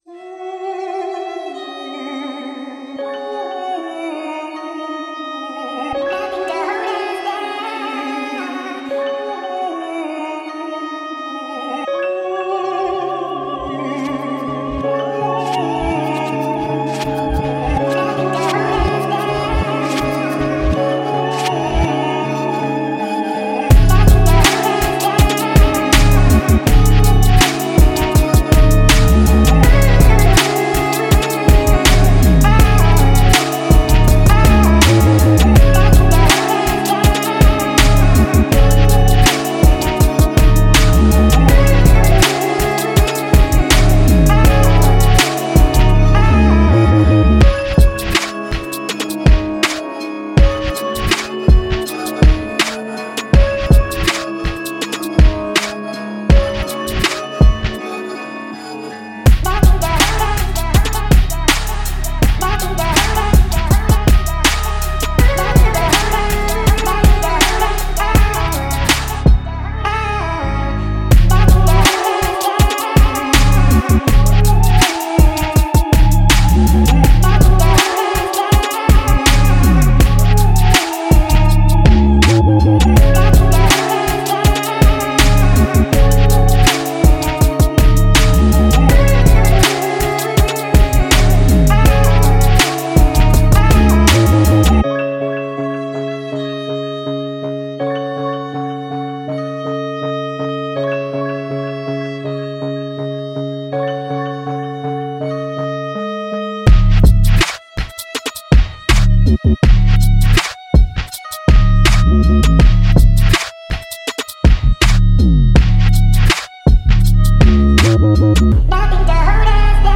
This song was inspired was a random sample I found and goofing around with a wobbled out 808. I added a few choir layers to make it sound spooky, like the past haunting you, and telling you "you can't"